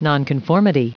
Prononciation du mot : nonconformity